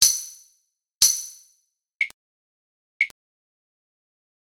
fairly loud pop or tick after many of the unpitched percussion instruments.
The pop occurs just as the note trails off.
clave notes end with a pop.